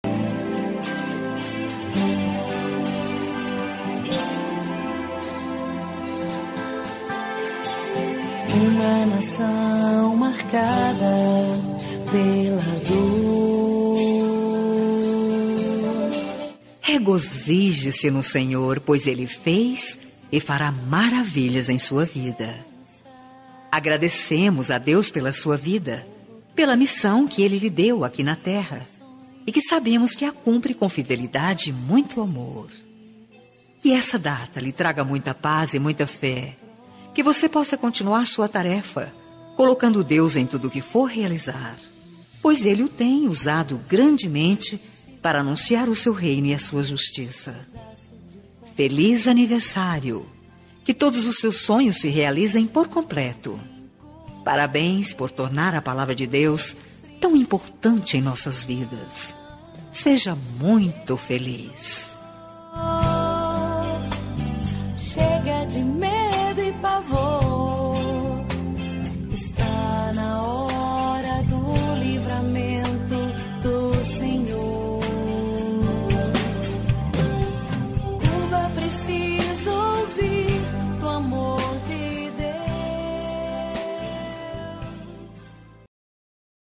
Aniversário de Pastora – Voz Feminina – Cód: 5103